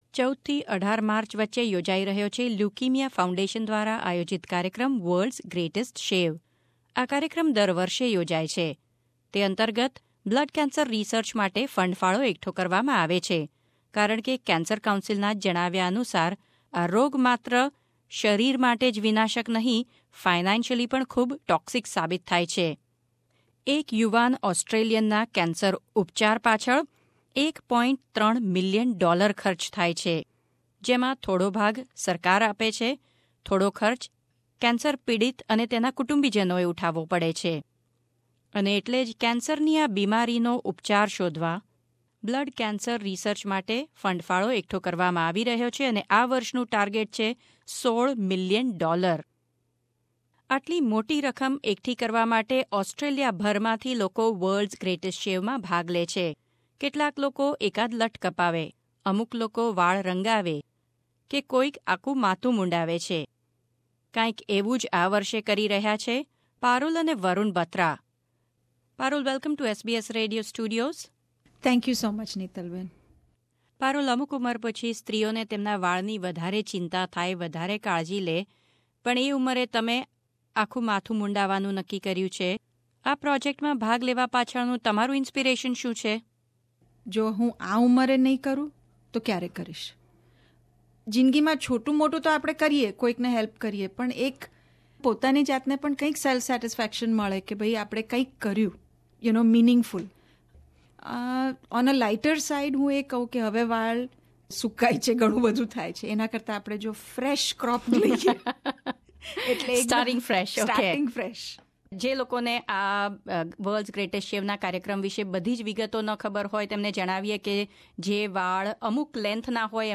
SBS Studio in Sydney